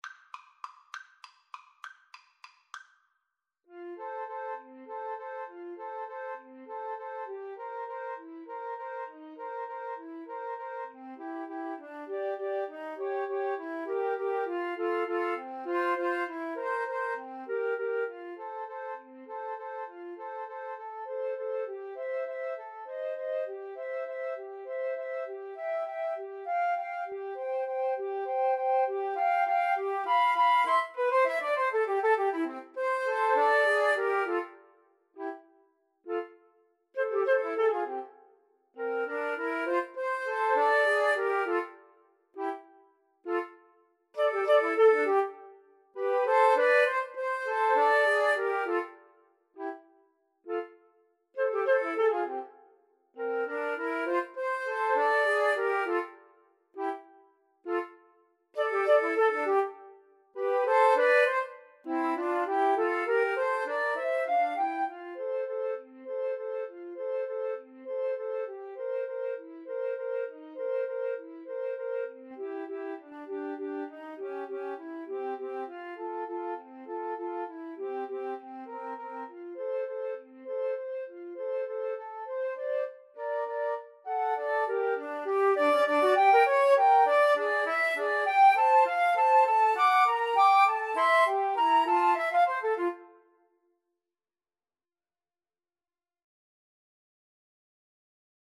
Free Sheet music for Flute Trio
F major (Sounding Pitch) (View more F major Music for Flute Trio )
Classical (View more Classical Flute Trio Music)